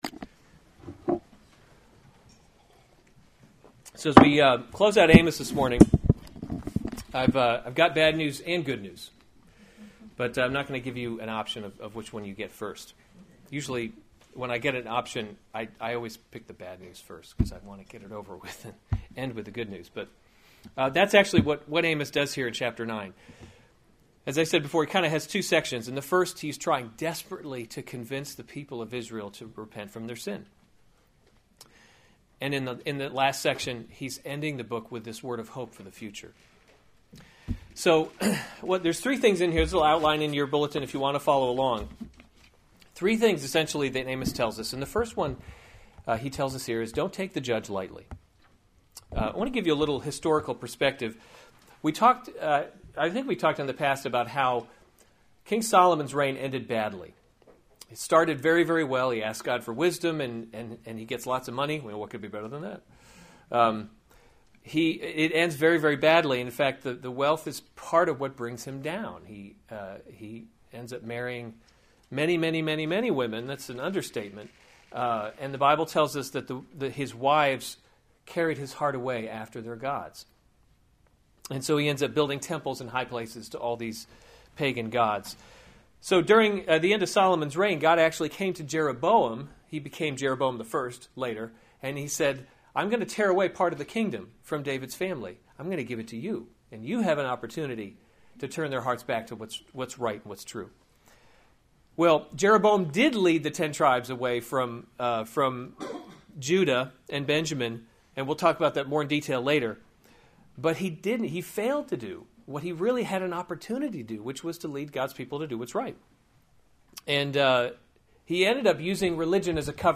November 21, 2015 Amos: He’s Not a Tame Lion series Weekly Sunday Service Save/Download this sermon Amos 9:1-15 Other sermons from Amos The Destruction of Israel 9:1 I saw the Lord […]